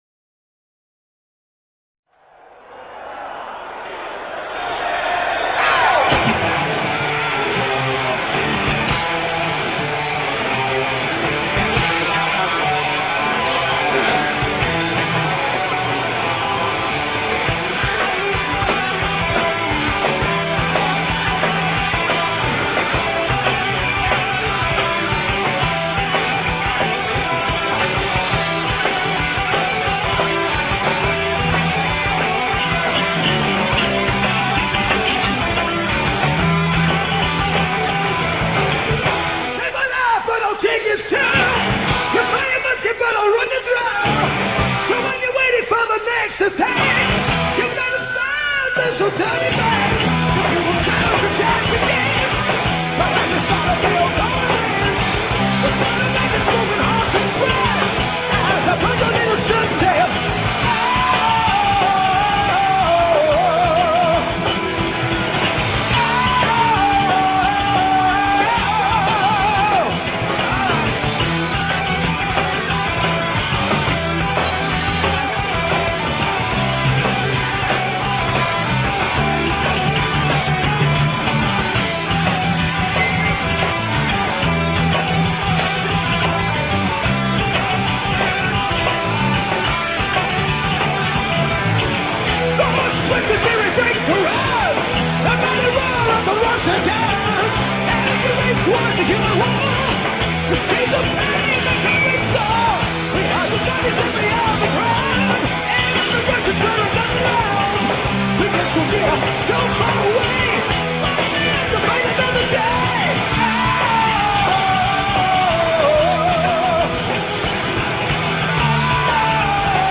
MayDay Festival 1993, Italy